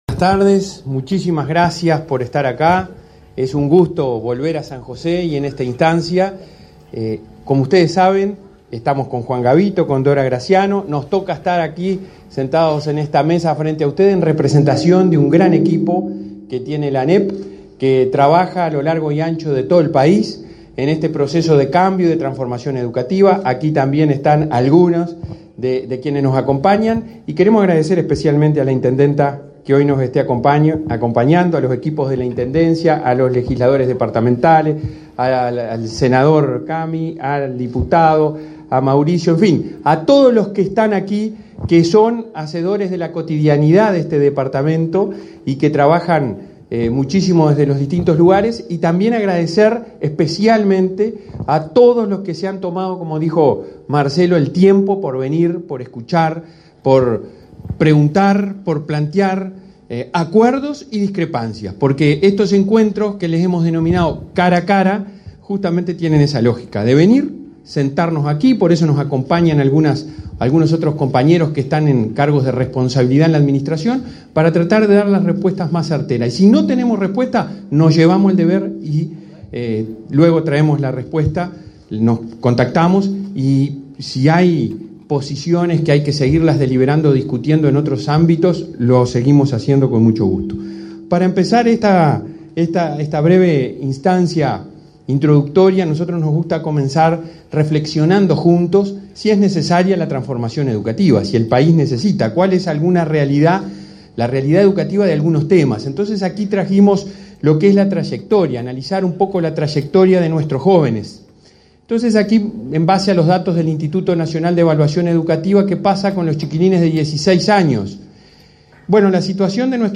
Palabras del presidente del Codicen de la ANEP, Robert Silva, en San José
Palabras del presidente del Codicen de la ANEP, Robert Silva, en San José 16/09/2022 Compartir Facebook X Copiar enlace WhatsApp LinkedIn El presidente del Consejo Directivo Central (Codicen) de la Administración Nacional de Educación Pública (ANEP), Robert Silva, lideró, este 16 de setiembre, un nuevo encuentro Cara a Cara con la Comunidad, esta vez en San José, donde se presentaron los ejes estratégicos de la transformación educativa.